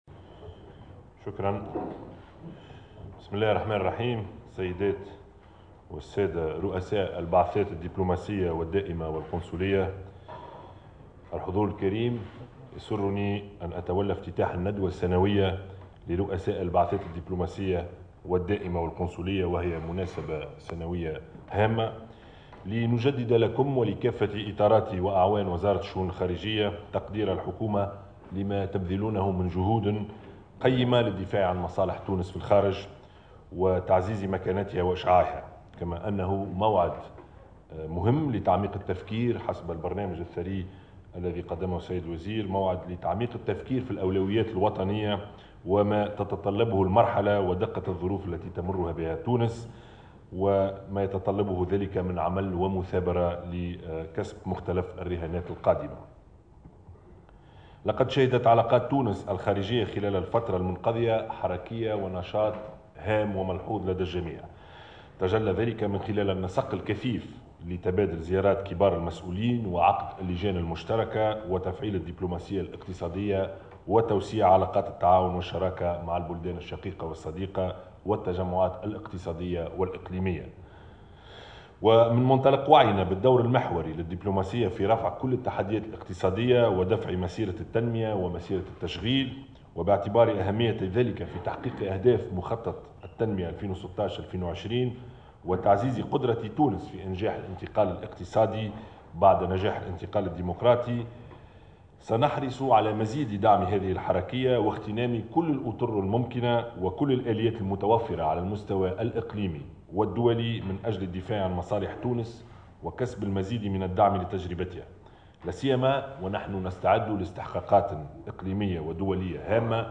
(كلمة رئيس الحكومة بالتسجيل المرفق)